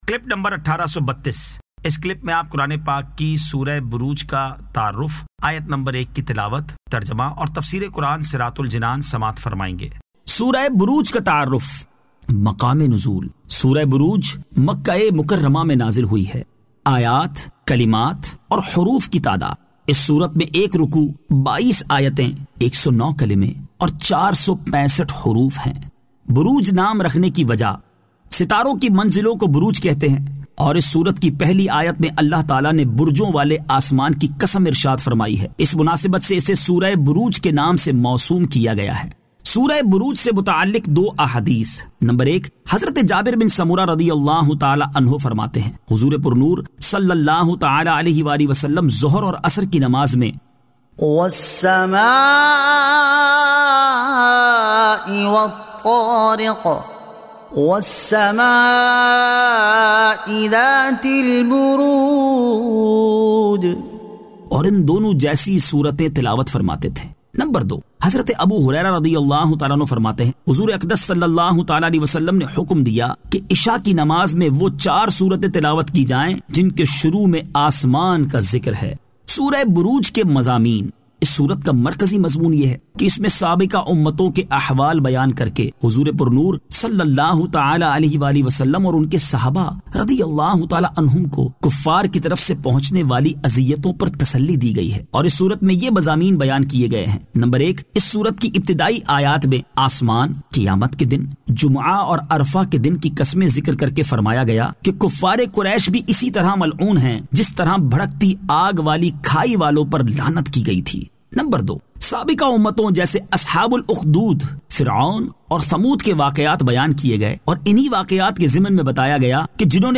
Surah Al-Burooj 01 To 01 Tilawat , Tarjama , Tafseer